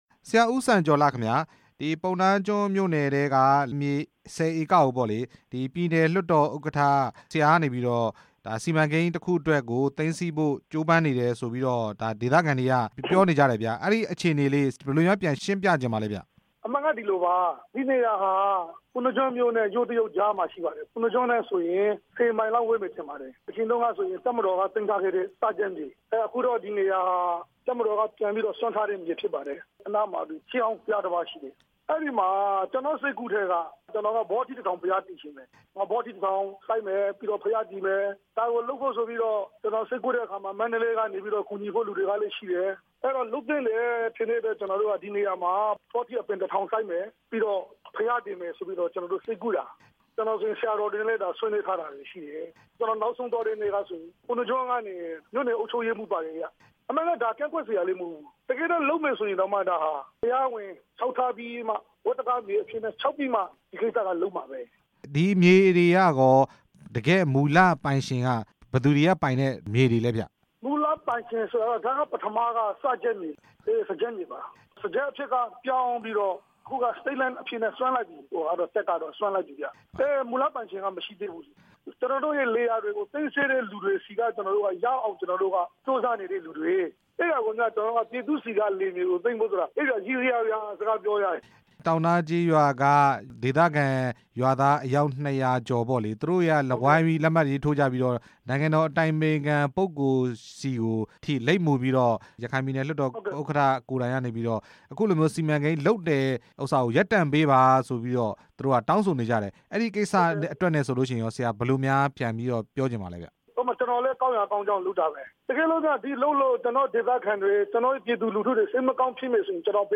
ဒေသခံတွေ သဘောမတူရင် ဆက်လုပ်မှာမဟုတ်ကြောင်း ရခိုင်ပြည်နယ်လွှတ်တော်ဥက္ကဌ ဦးစံကျော်လှ က ပြောပါတယ်။